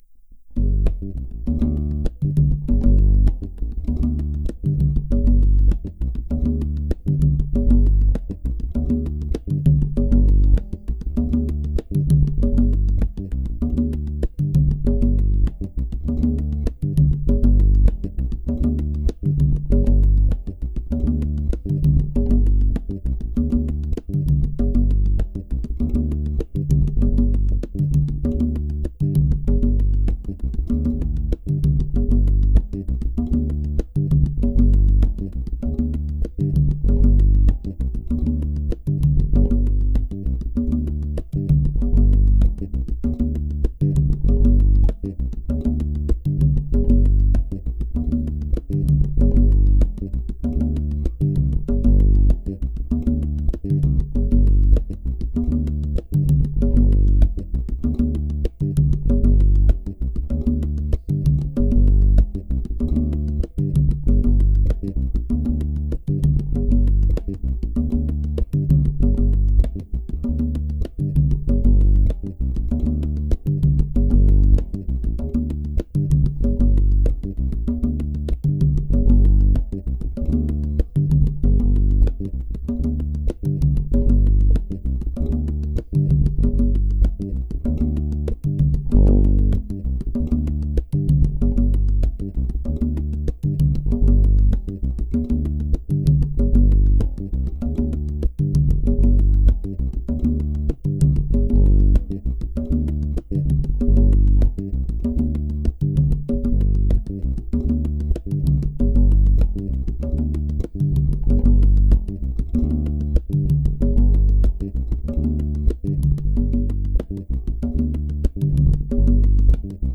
Performance 2, Bass Ahead of the Congas – Audio Example 2
These small differences are detectable on listening.
A feeling of the congas slowing gradually throughout the take.
Sounds like it starts to slow at 53 seconds.